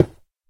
Minecraft / dig / stone3.ogg
Current sounds were too quiet so swapping these for JE sounds will have to be done with some sort of normalization level sampling thingie with ffmpeg or smthn 2026-03-06 20:59:25 -06:00 8.4 KiB Raw History
stone3.ogg